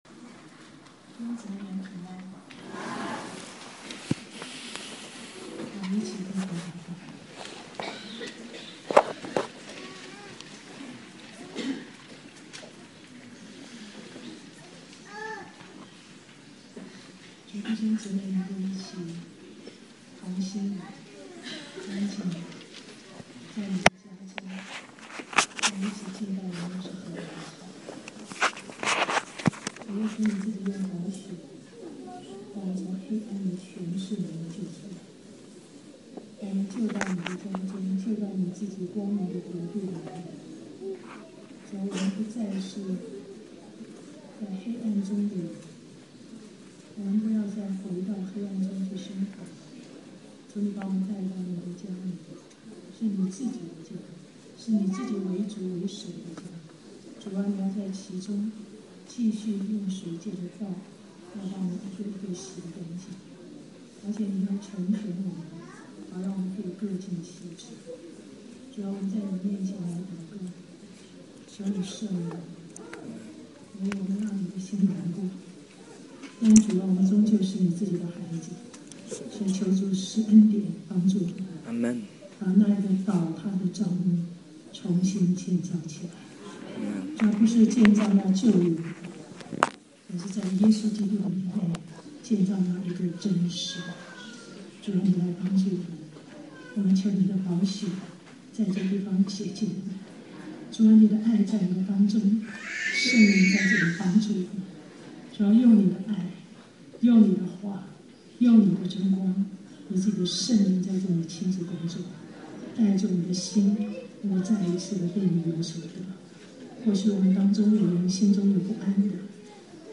2011年2月27日巴黎温州教会主日下午讲道 - Powered by Discuz!